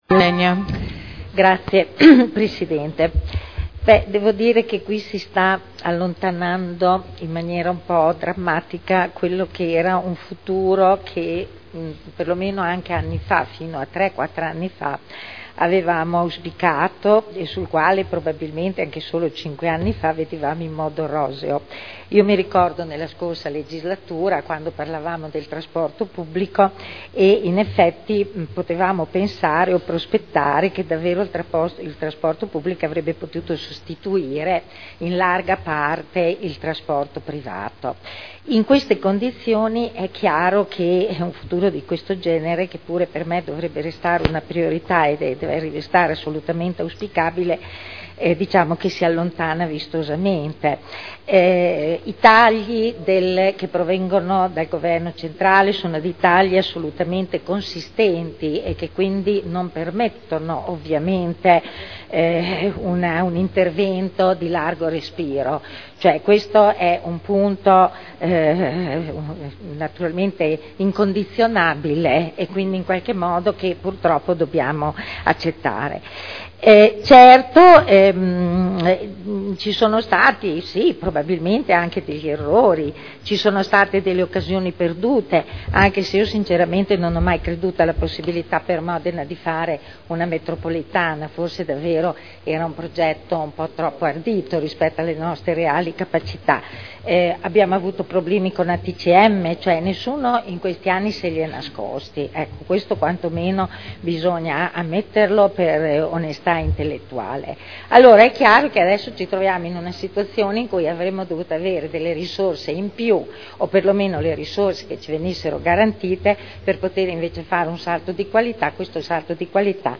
Seduta del 21/02/2011. Indirizzi per la gestione del trasporto pubblico locale a seguito del patto per il trasporto pubblico regionale e locale in Emilia Romagna per il triennio 2011/2013 – aumenti tariffari per il Comune di Modena – Approvazione discussione
Audio Consiglio Comunale